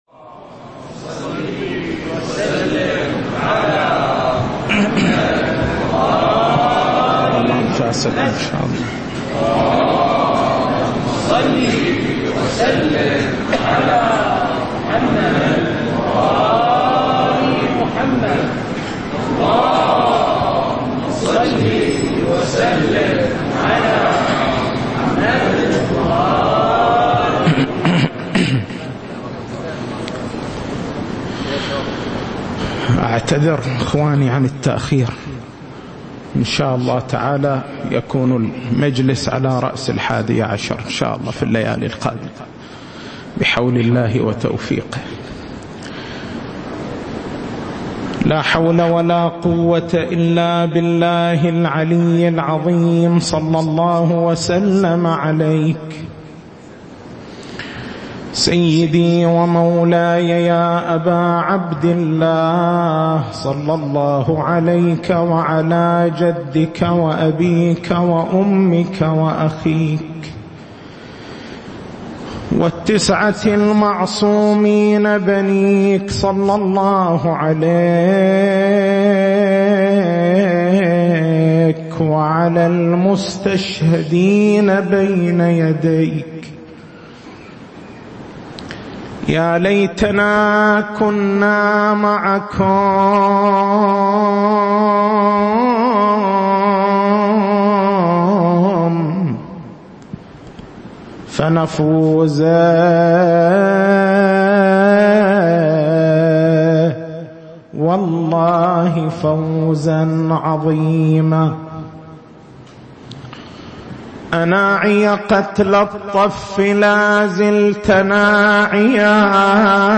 تاريخ المحاضرة: 03/09/1437 نقاط البحث: ما هو المقصود من فقه الأسرة؟ معنى الأسرة لغةً واصطلاحًا معنى فقه الأسرة هل يوجد نظام أسريّ في الإسلام؟